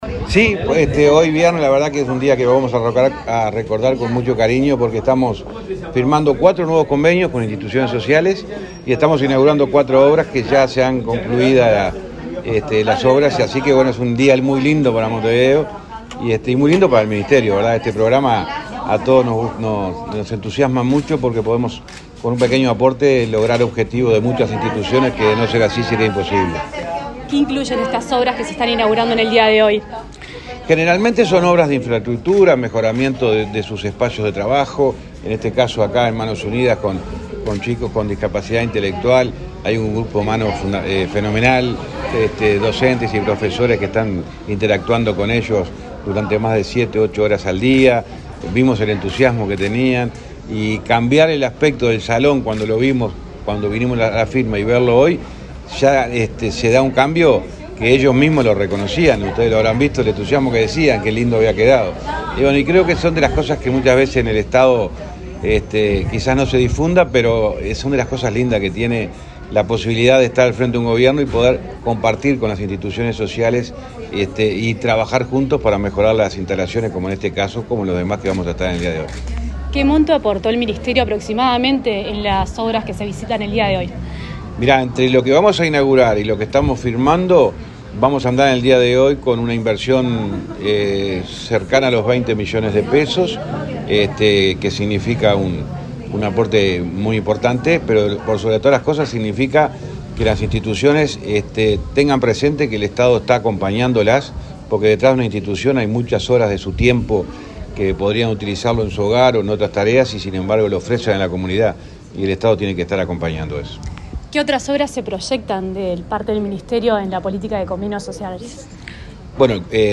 Entrevista al ministro de Transporte, José Luis Falero
Entrevista al ministro de Transporte, José Luis Falero 21/04/2023 Compartir Facebook X Copiar enlace WhatsApp LinkedIn El ministro de Transporte, José Luis Falero, dialogó con Comunicación Presidencial durante una recorrida por Montevideo, donde firmó varios acuerdos e inauguró obras, en el marco del programa Convenios Sociales de esa cartera.